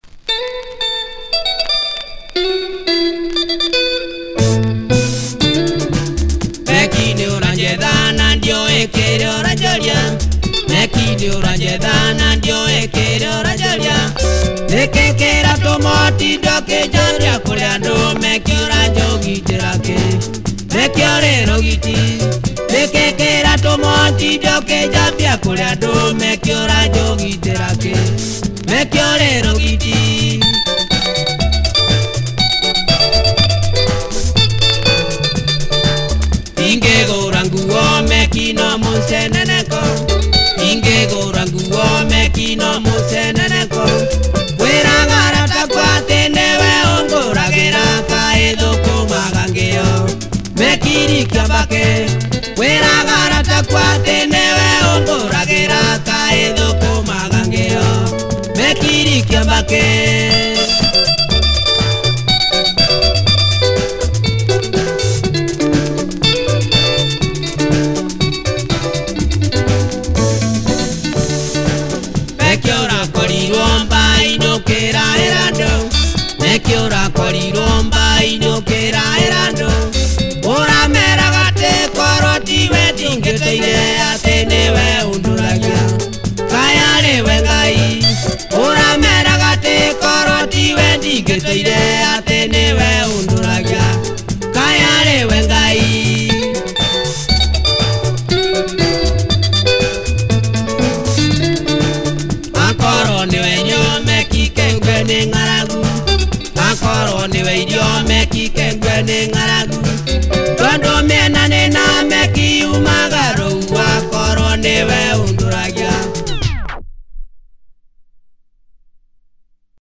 hard drums, catchy vocals. https